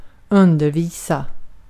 Ääntäminen
UK : IPA : /tiːtʃ/ US : IPA : /titʃ/